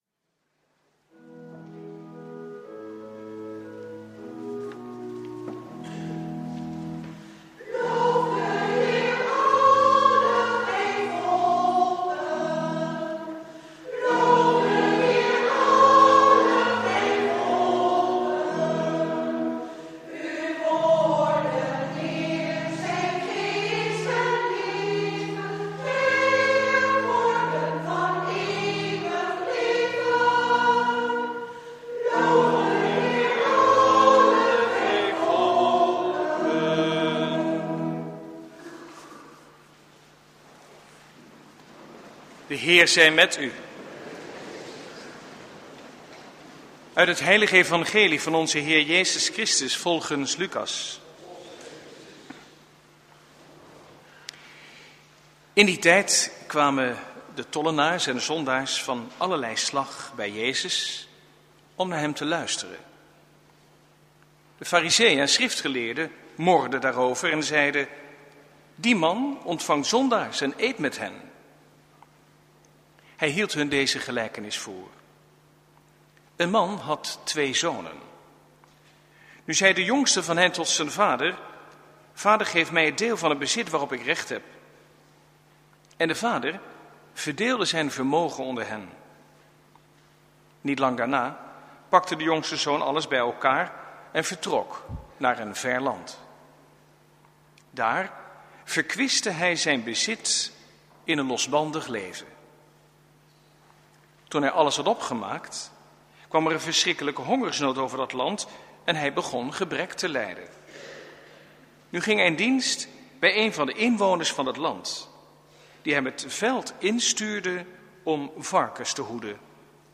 Lezingen